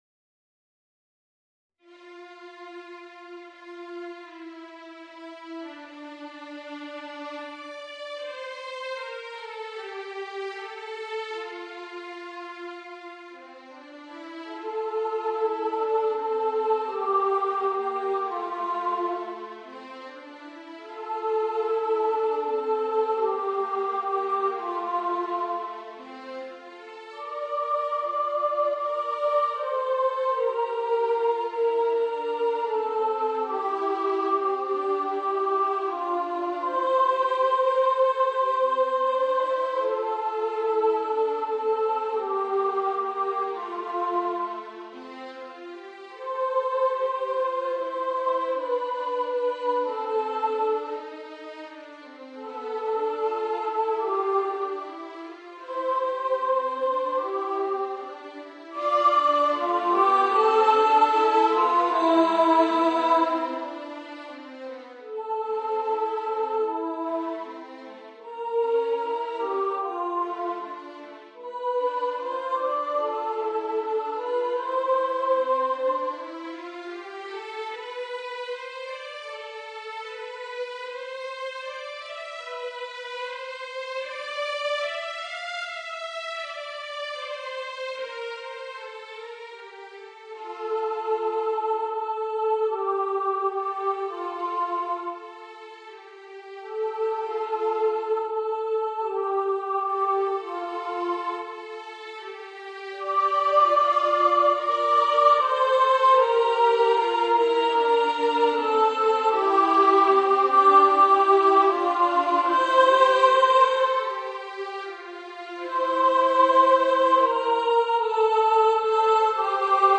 Voicing: Violin and Voice